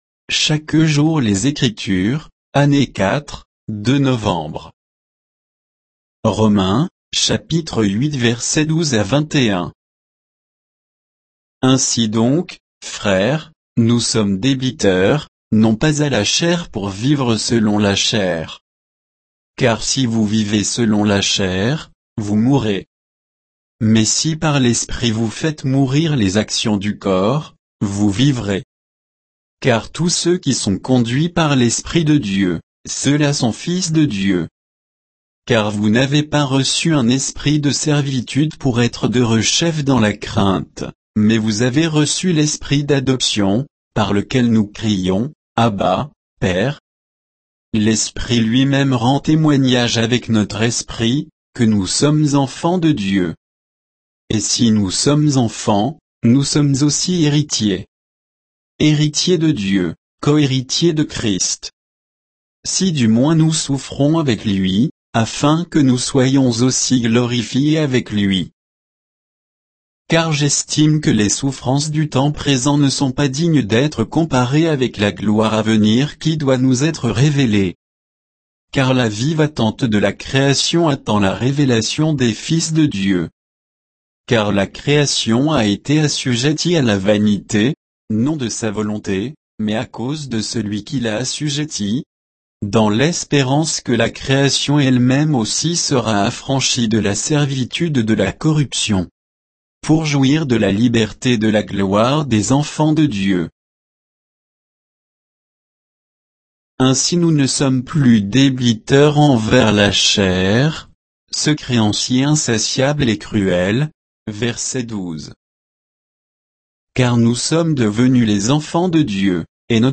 Méditation quoditienne de Chaque jour les Écritures sur Romains 8